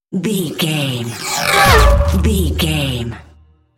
Sci fi vehicle whoosh fast
Sound Effects
futuristic
intense
whoosh